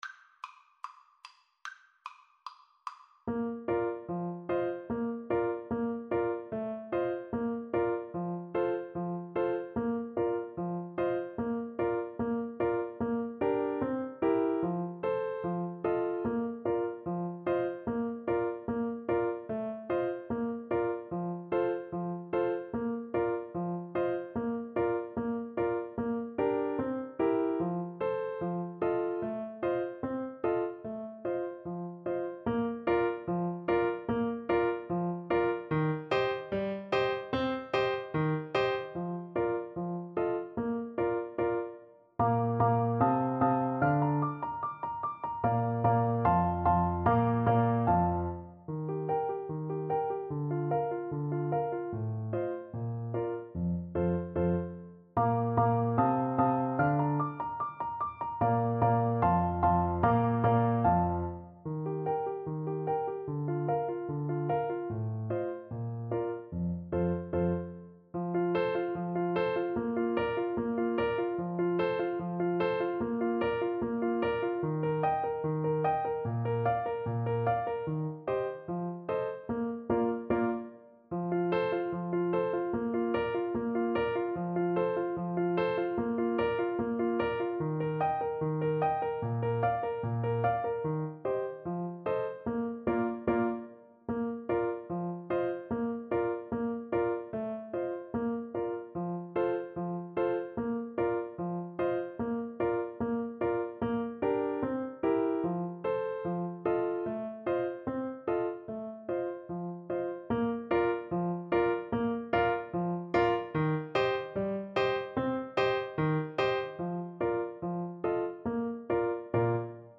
Allegretto = 74
Classical (View more Classical Tenor Saxophone Music)